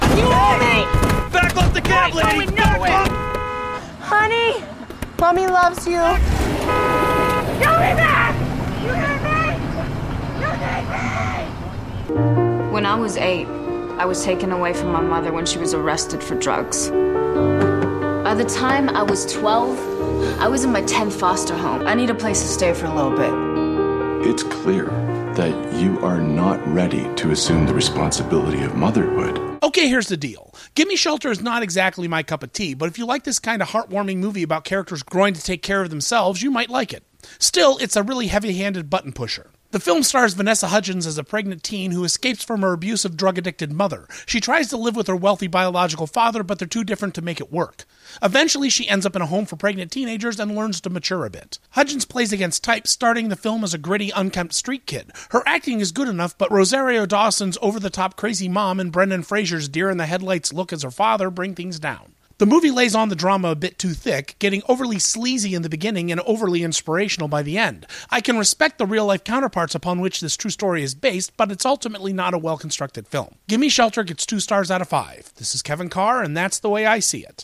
‘Gimme Shelter’ Movie Review